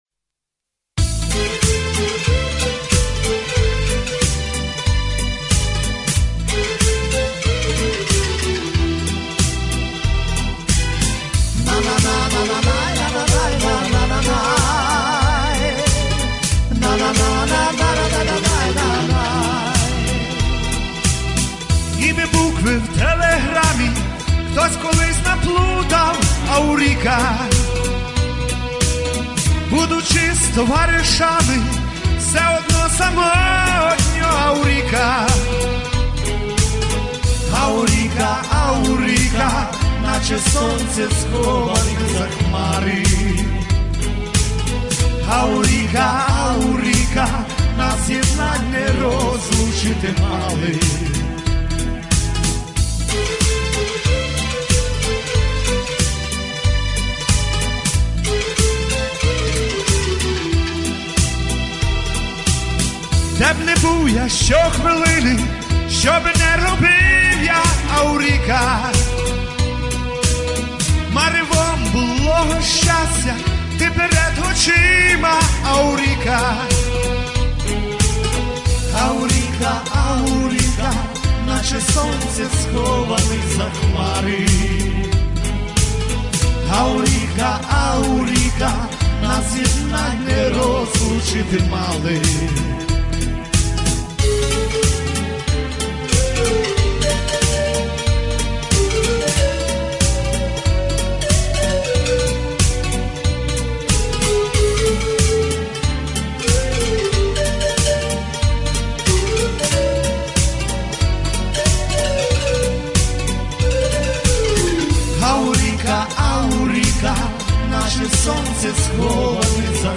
Помірна
Соло
Чоловіча
Аматорська
Авторська.